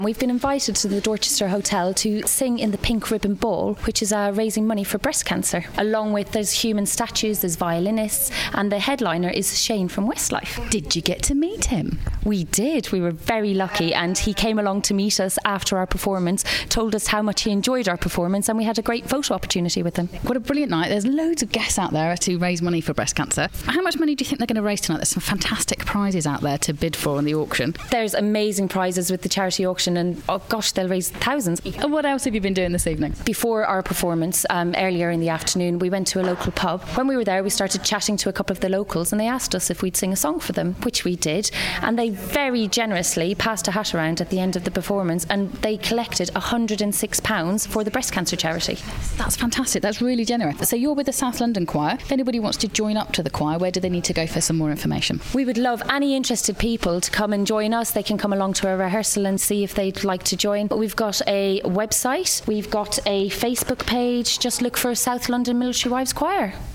Pink Ribbon Ball 2016
The Pink Ribbon Ball was held on Saturday at the prestigious London Hotel, the Dorchester.